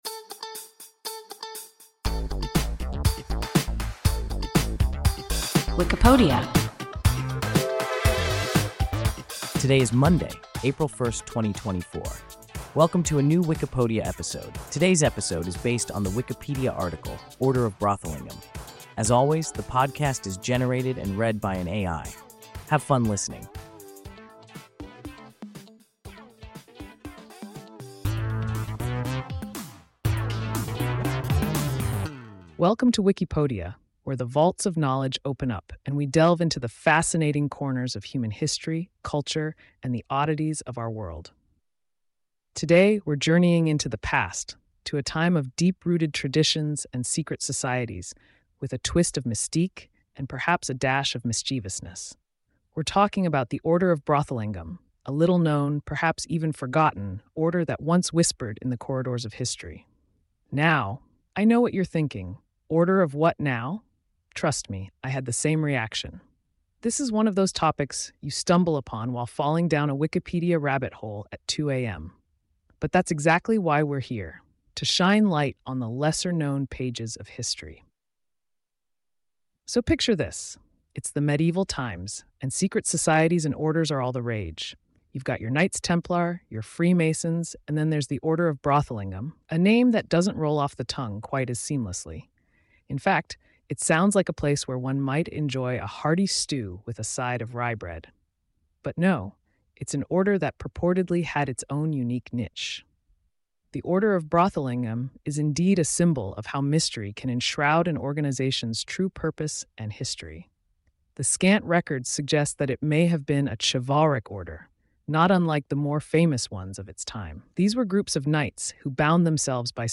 Order of Brothelyngham – WIKIPODIA – ein KI Podcast